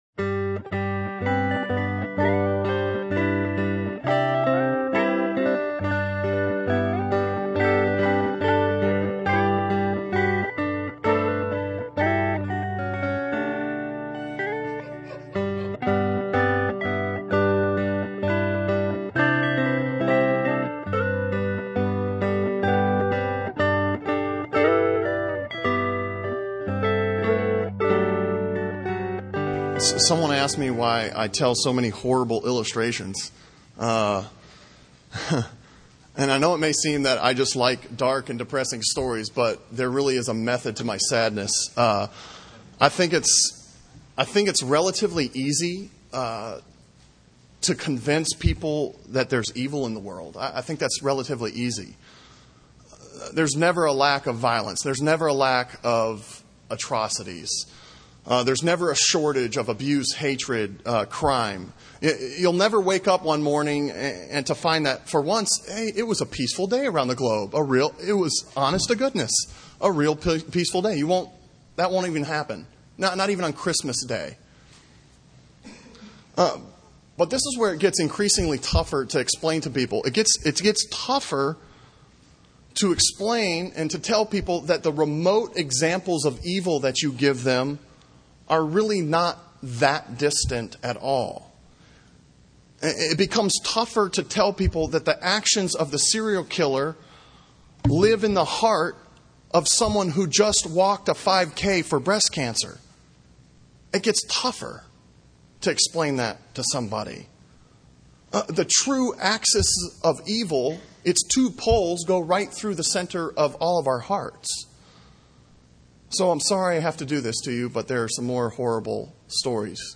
and It Is Sermon from August 31